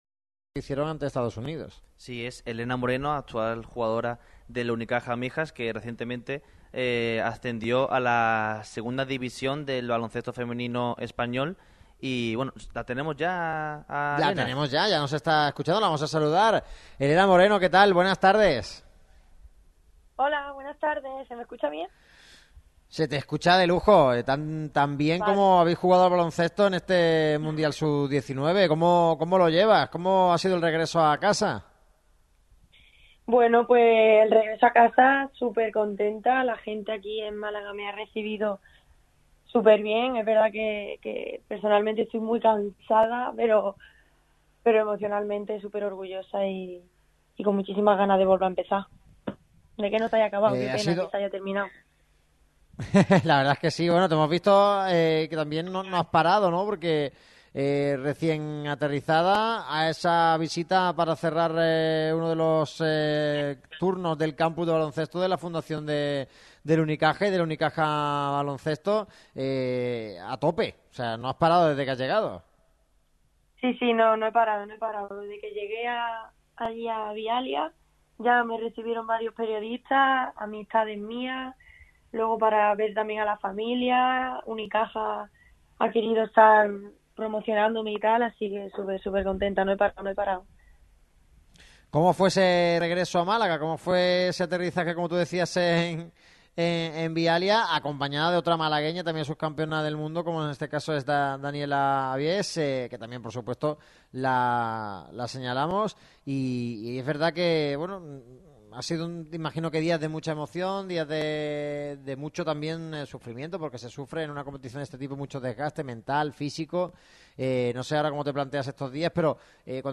En el día de hoy, hemos realizado el programa en Grupo Sertasa, hogar de la gama de coches Jaguar y Land Rover.